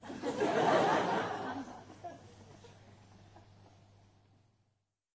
剧院 " 笑2
描述：LaughLaugh在中型剧院用MD和索尼麦克风录制，在人的上方
Tag: 听众 礼堂 人群 捷克 布拉格 戏剧